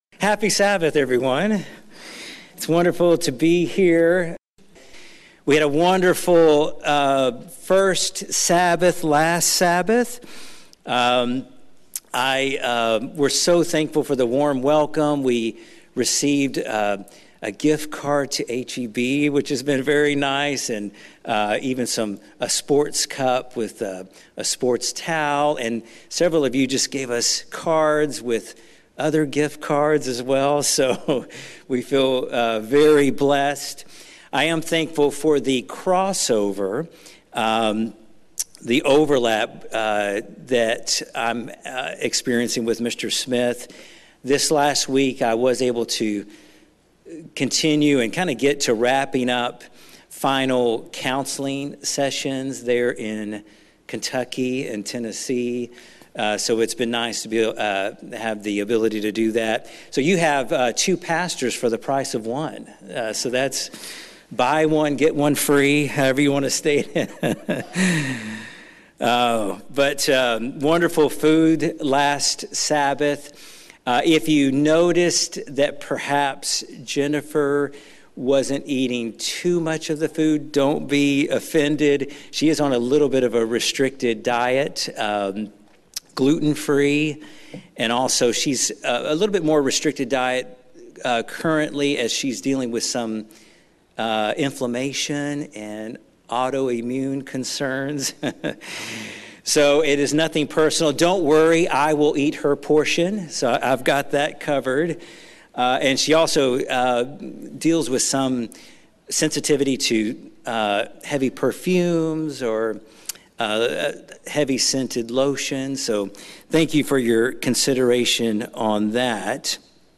The title of this sermon seems strange to us. Most of us do not consider our weaknesses as assets, but rather as liabilities.